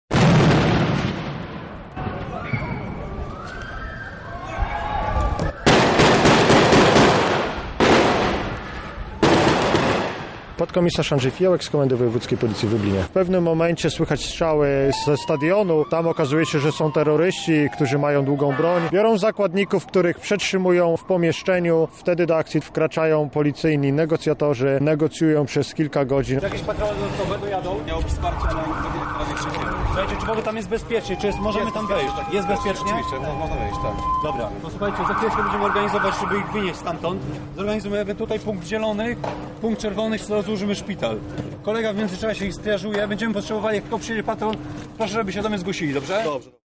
Sprawdzała nasza reporterka
Ćwiczenia na Arenie Lublin